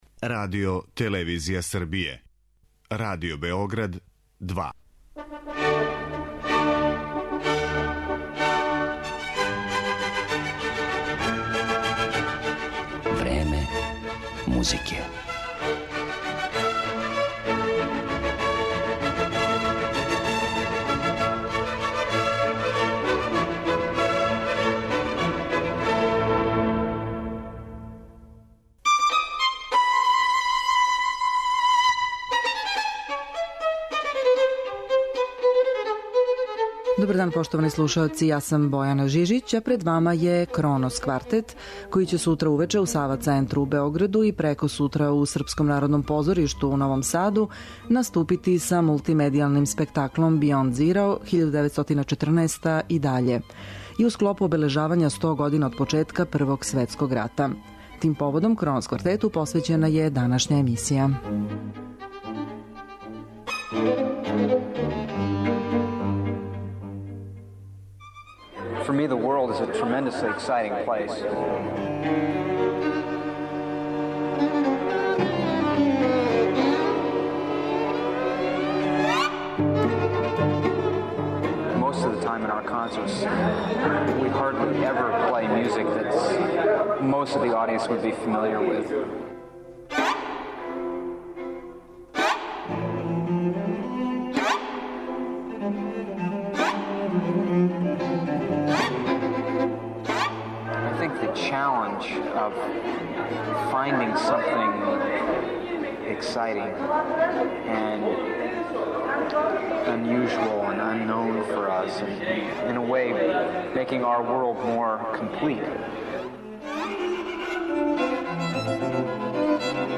Кронос квартет ће у четвртак, 6. новембра, одржати концерт у Сава Центру у Београду, а у петак, 7. новембра, у Српском народном позоришту у Новом Саду, и тим поводом му је посвећена данашња емисија, у којој ћете моћи да чујете и серију интервјуа остварених са оснивачем и првим виолинистом ансамбла, Дејвидом Херингтоном. Интервјуи су забележени у четири наврата: од 1994. године у Истанбулу, до 2012. у Новом Саду.